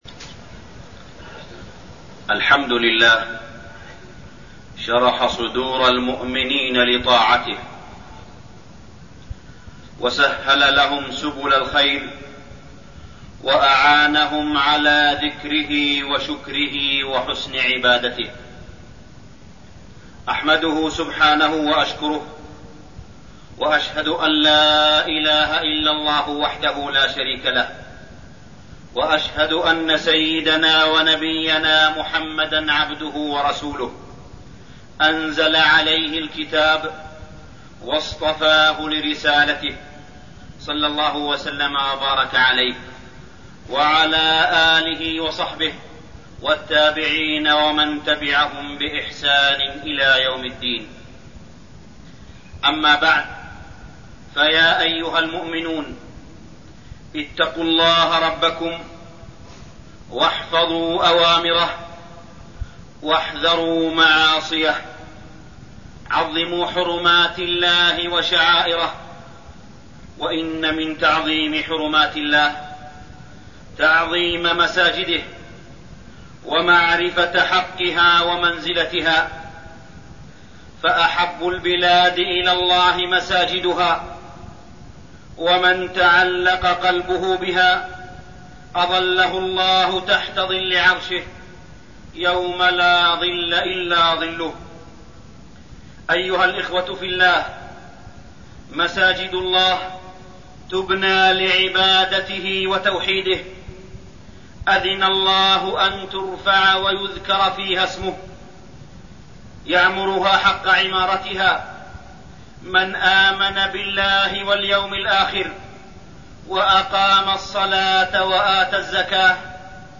تاريخ النشر ٢٠ رمضان ١٤٠٨ هـ المكان: المسجد الحرام الشيخ: معالي الشيخ أ.د. صالح بن عبدالله بن حميد معالي الشيخ أ.د. صالح بن عبدالله بن حميد غزوة بدر الكبرى